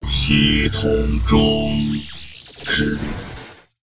文件 文件历史 文件用途 全域文件用途 Enjo_die.ogg （Ogg Vorbis声音文件，长度3.8秒，98 kbps，文件大小：46 KB） 源地址:地下城与勇士游戏语音 文件历史 点击某个日期/时间查看对应时刻的文件。